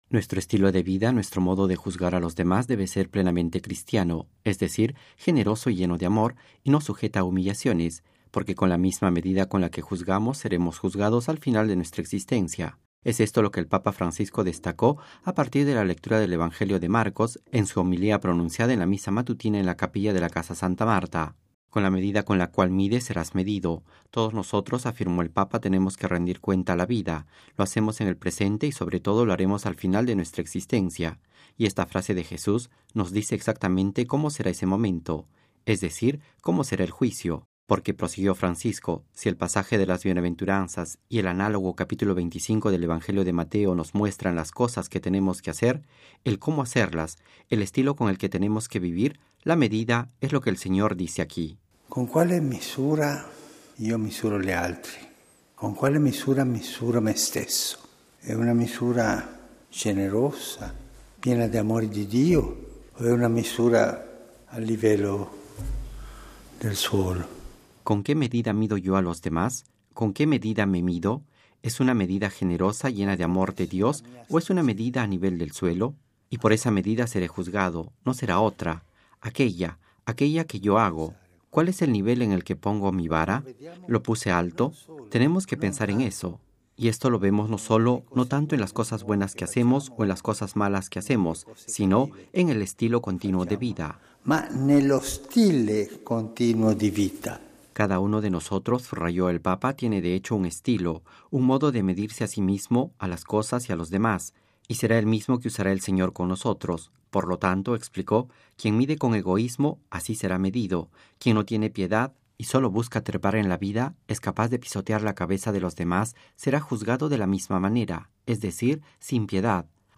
Audio: Homilía del Papa en la Misa en la Casa Santa Marta